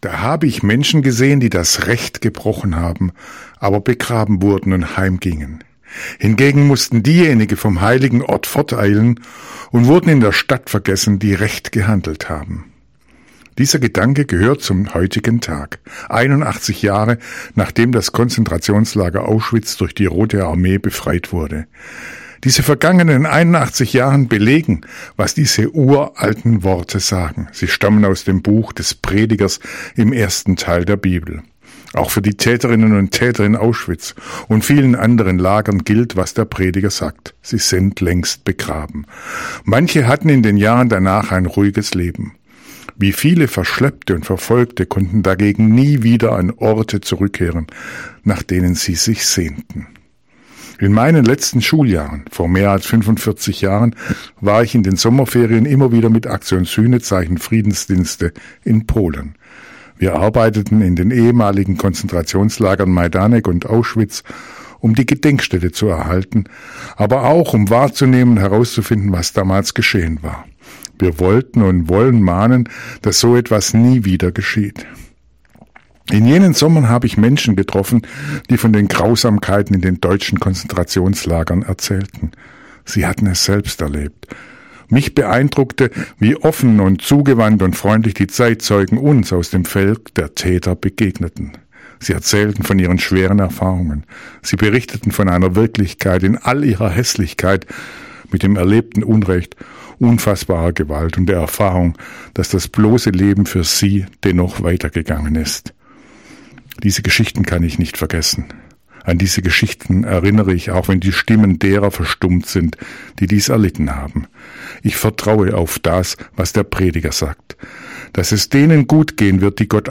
Radioandacht vom 27. Januar